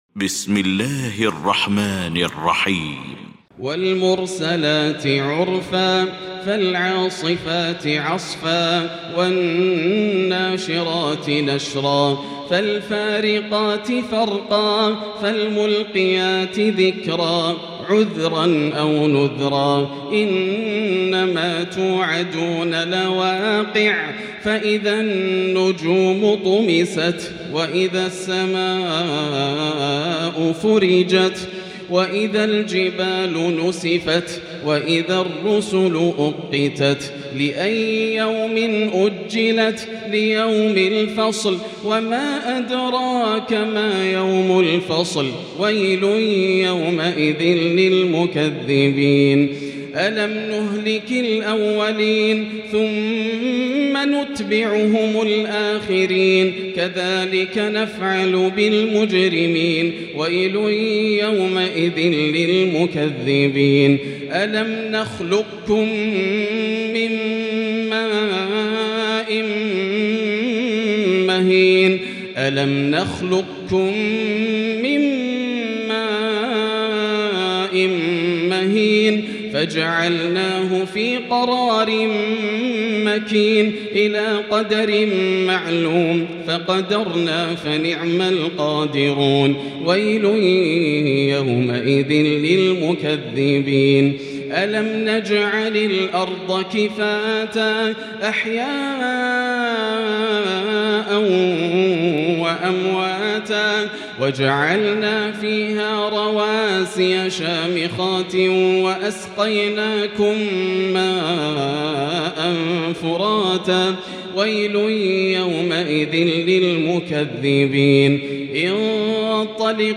المكان: المسجد الحرام الشيخ: فضيلة الشيخ ياسر الدوسري فضيلة الشيخ ياسر الدوسري المرسلات